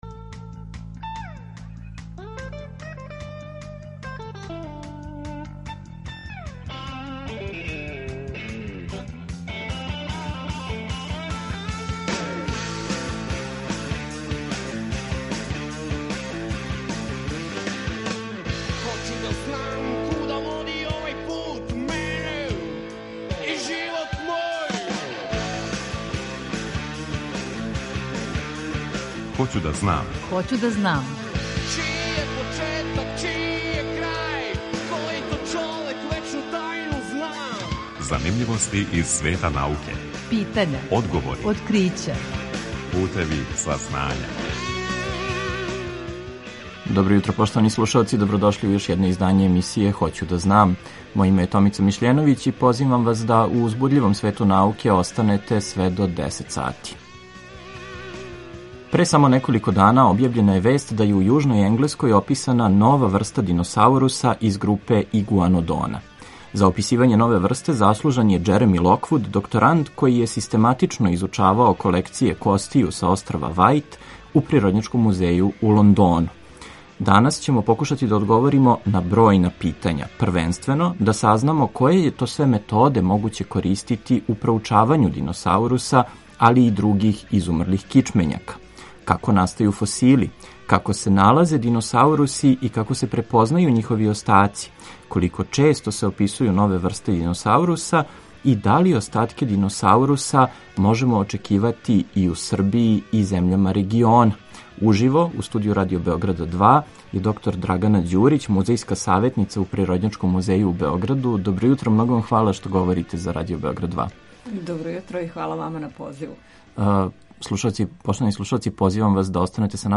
Са нашом саговорницом разговараћемо о томе које је све методе могуће користити у проучавању диносауруса, али и других изумрлих кичмењака. У разговору ћемо одговорити и на бројна друга питања.